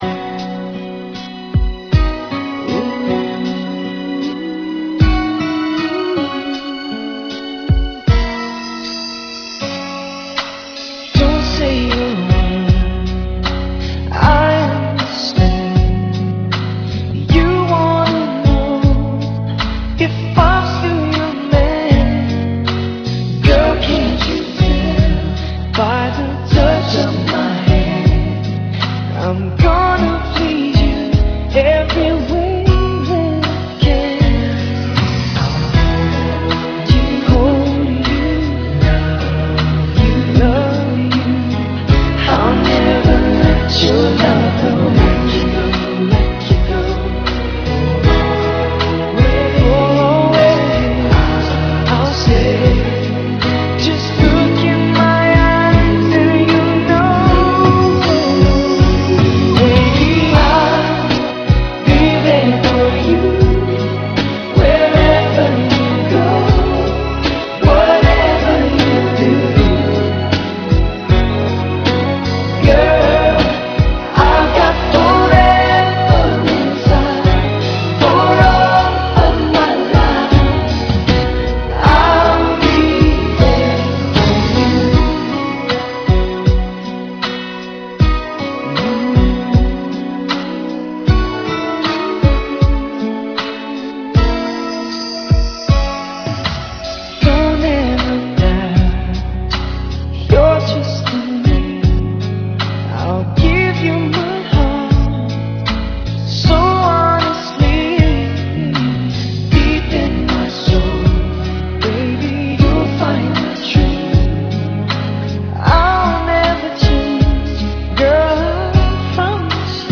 I have a feeling a bit of this is cut off at the end...